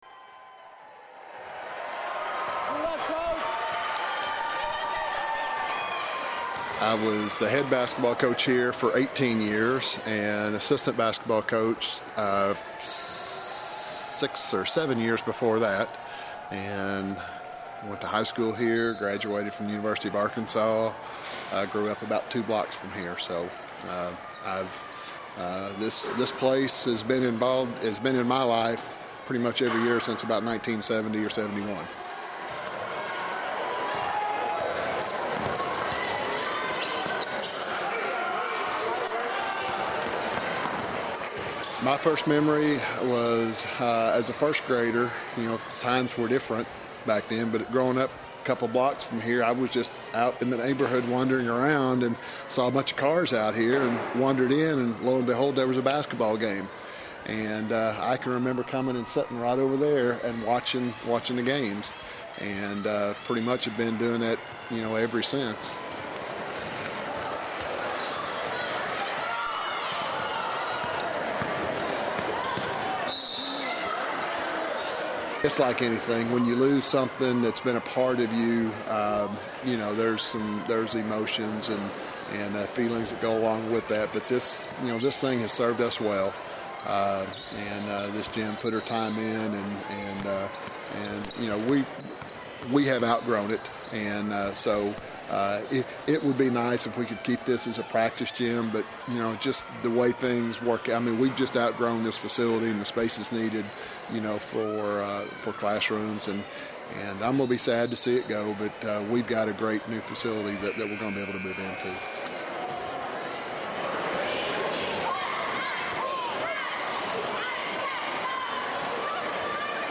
Crowds at Fayetteville High School cheered for its athletes at its old gymnasium one last time last Friday. The building is set to be demolished next month.
FHSGym_FinalGame.mp3